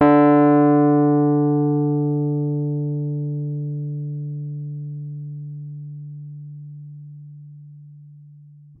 Rhodes_MK1